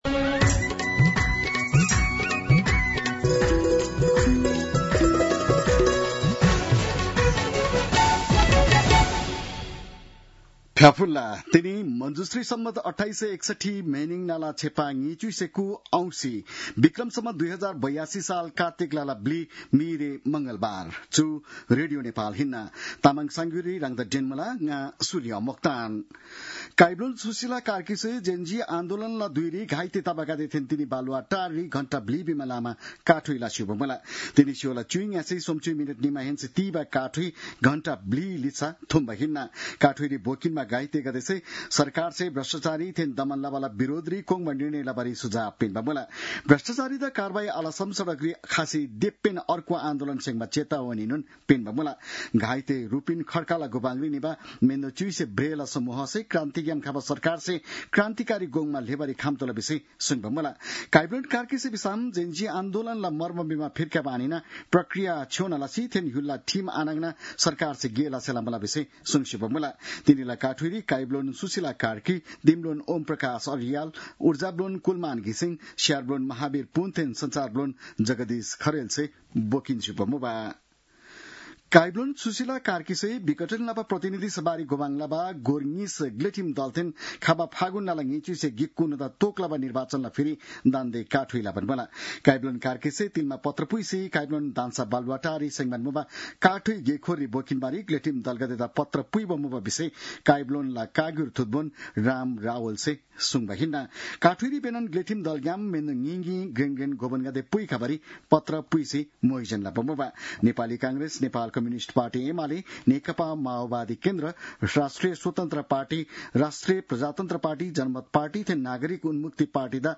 तामाङ भाषाको समाचार : ४ कार्तिक , २०८२